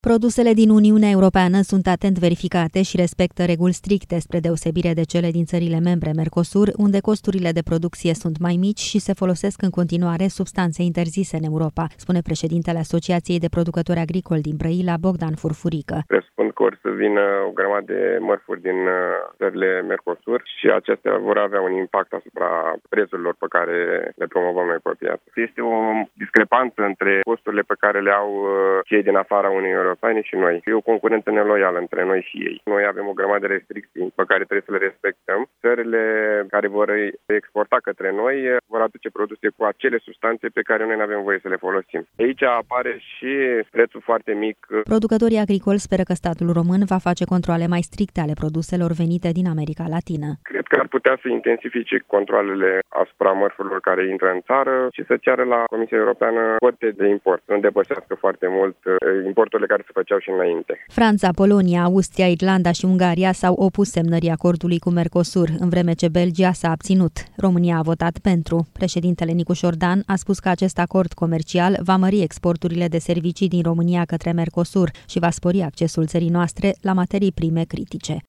Într-un interviu acordat Europa FM, acesta a atras atenția asupra concurenței neloiale care va apărea în urma acordului comercial cu Brazilia, Argentina, Paraguay și Uruguay.